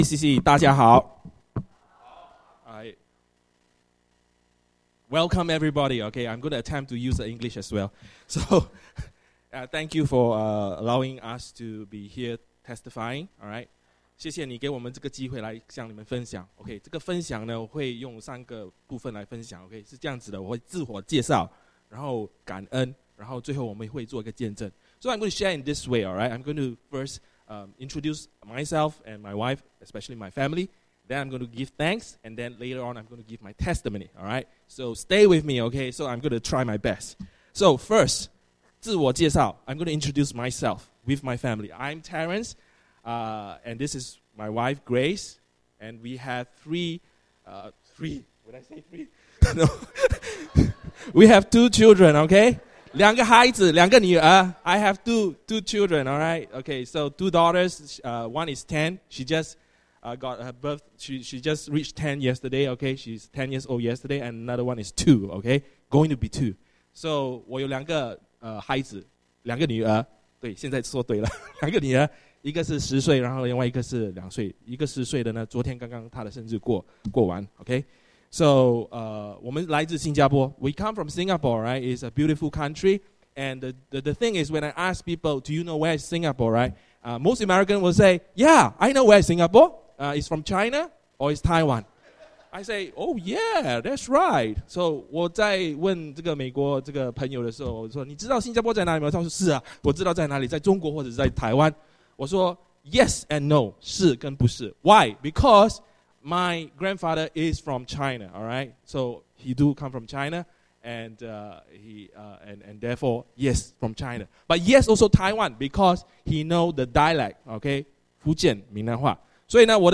170702: 见证分享